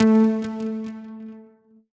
synTTE55011shortsyn-A.wav